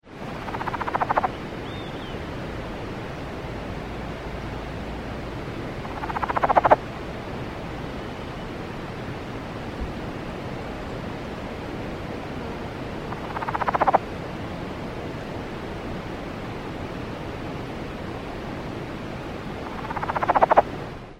Cascades Frog - Rana cascadae
The advertisement call of Rana cascadae is a quiet series of low grating clucking noises.
The following sounds were recorded on a sunny afternoon in early July at aprox. 5500 ft. in Pierce County Washington.
The sounds of running water, the occasional song of an Olive-sided Flycatcher and other birds, and insects can be heard in the background.
Sounds  This is 21 seconds of the advertisement calls of one frog.
rcascadaelong.mp3